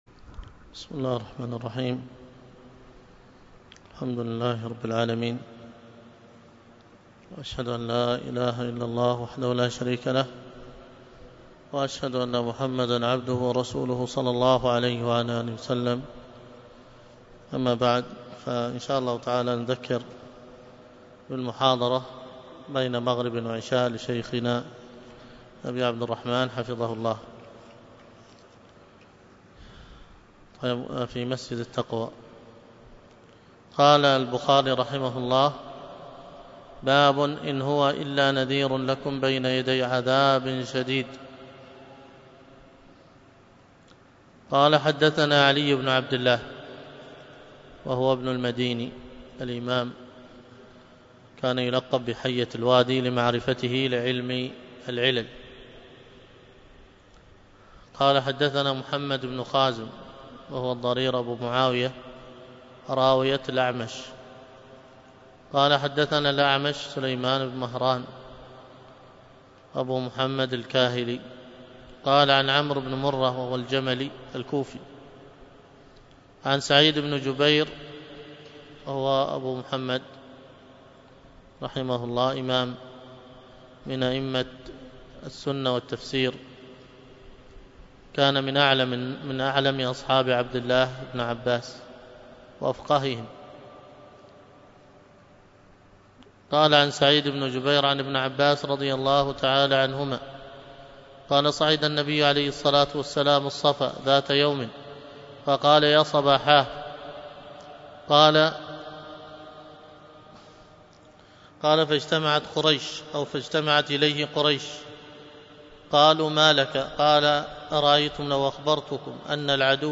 الدروس الفقه وأصوله